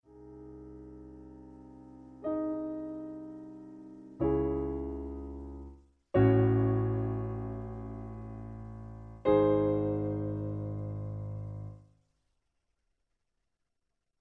Original Key. Piano Accompaniment